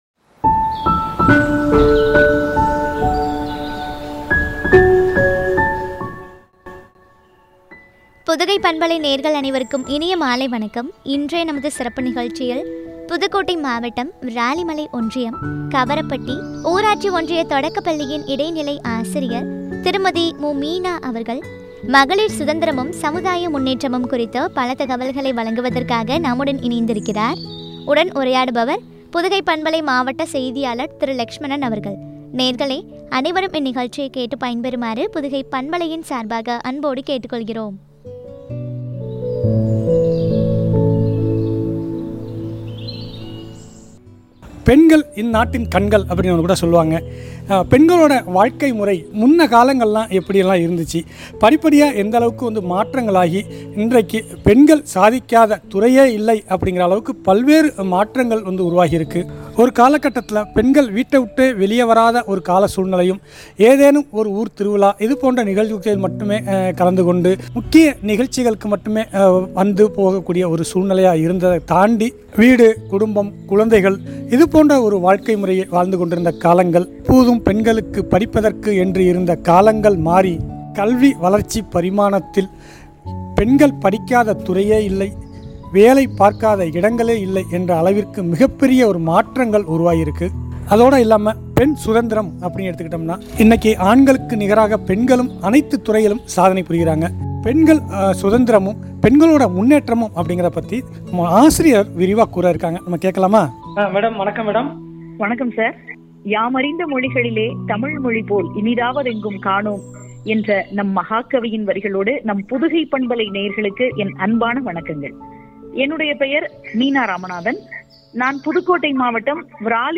மகளிர் சுதந்திரமும், சமுதாய முன்னேற்றமும் பற்றிய உரையாடல்.